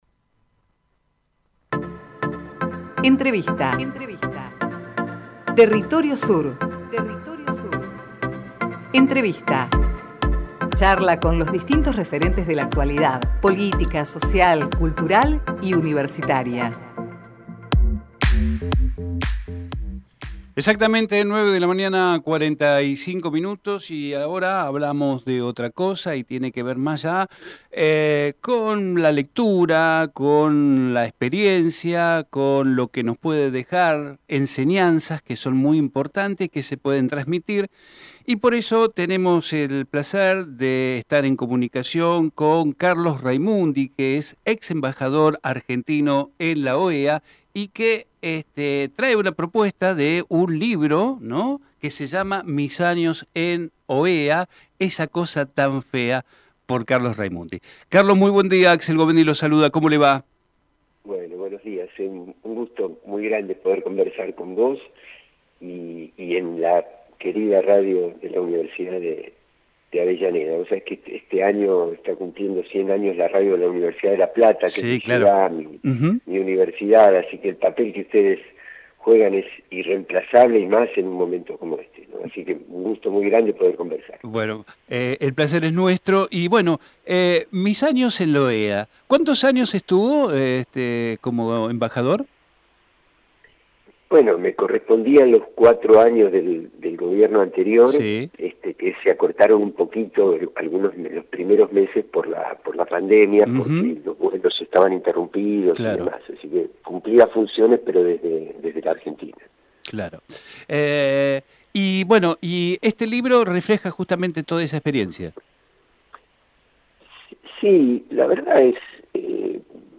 TERRITORIO SUR - CARLOS RAIMUNDI Texto de la nota: Compartimos con ustedes la entrevista realizada en Territorio Sur a Carlos Raimundi, ex embajador argentino en OEA Archivo de audio: TERRITORIO SUR - CARLOS RAIMUNDI Programa: Territorio Sur